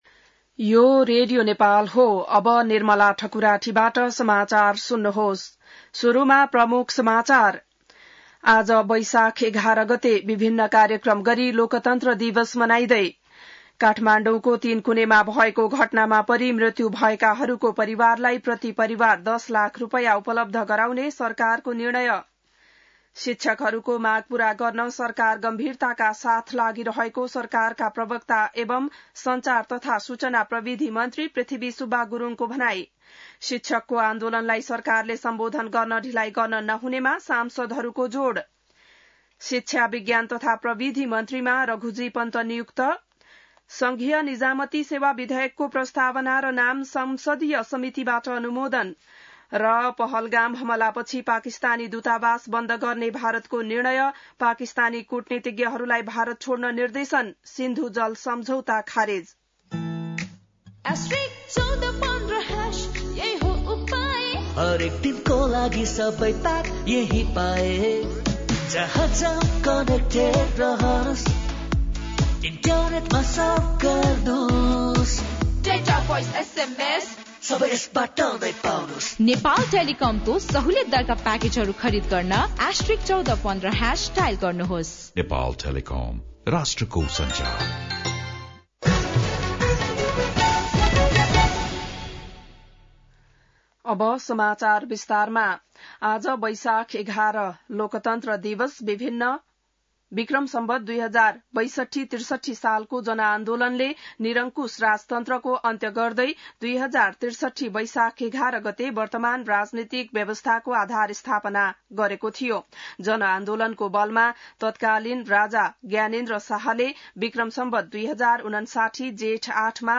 बिहान ७ बजेको नेपाली समाचार : ११ वैशाख , २०८२